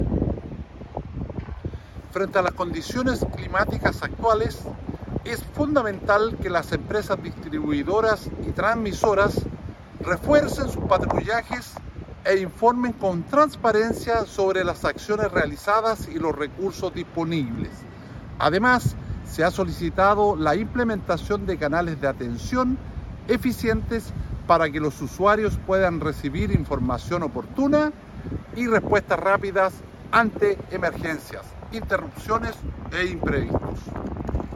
El seremi de Energía, Jorge Cáceres, subrayó la importancia de la coordinación y el compromiso de las empresas para enfrentar esta contingencia.
CUNA-SEREMI-DE-ENERGIA.mp3